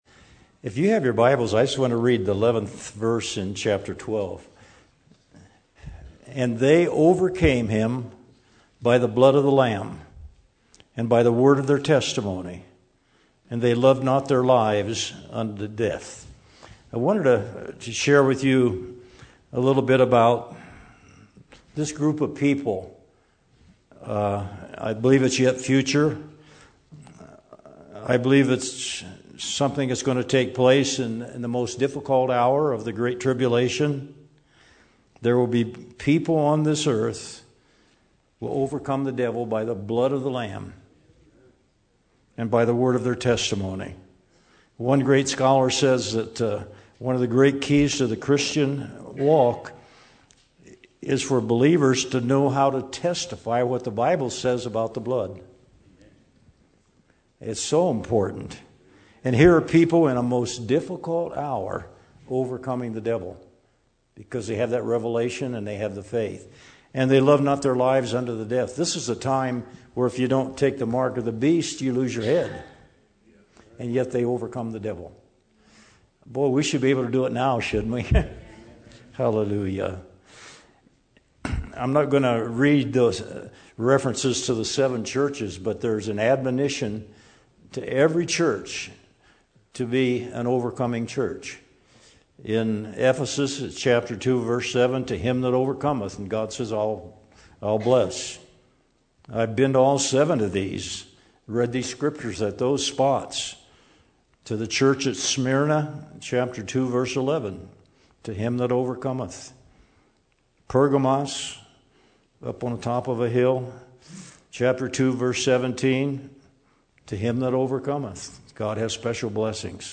Testimonies, teachings, sharing.